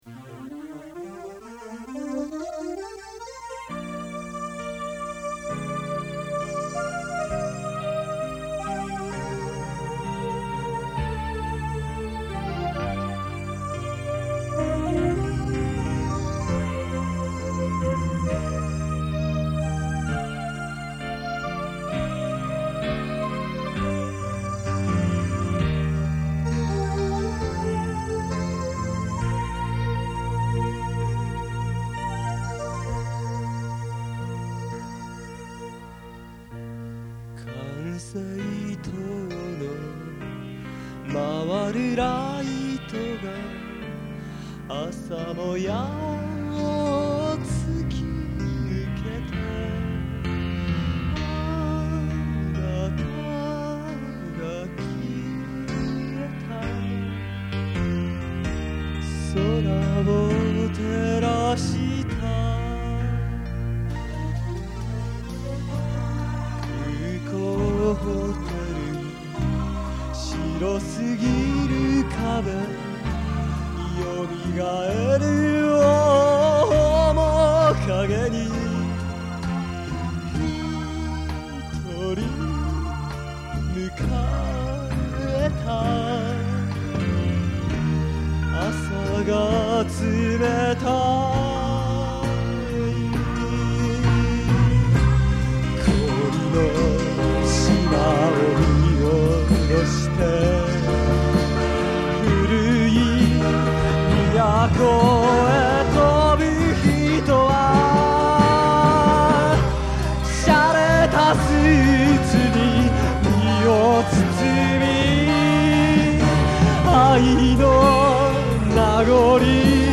ここで紹介させていただきますのは，もう２０余年前，大学の頃，ひとり軽音楽部の部室で，多重録音で作ったオリジナル曲です。
全部，自分でやってますし，おまけに，おそろしいことに Vocal まで，私自身でやっております（なんせ，男性サイドの歌詞ばかりなもので･･）。